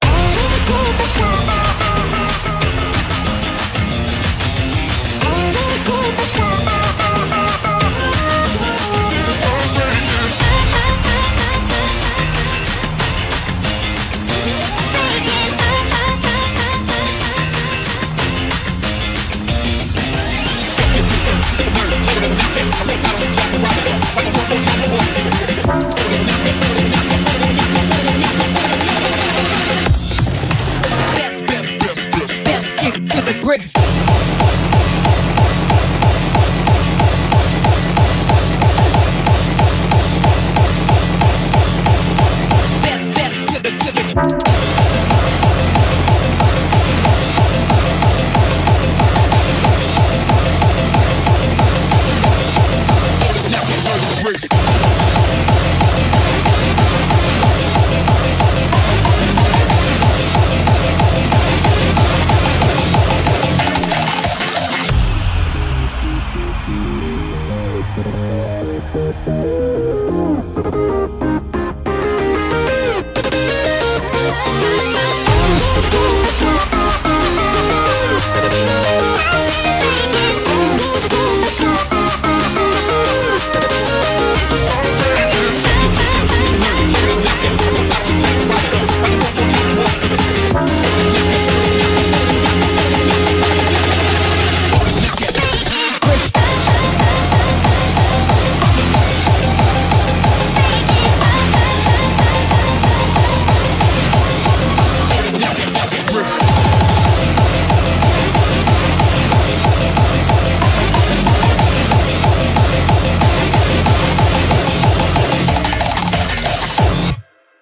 BPM185
Audio QualityPerfect (High Quality)
評論[IRREGULAR OMNIBUS HARDCORE]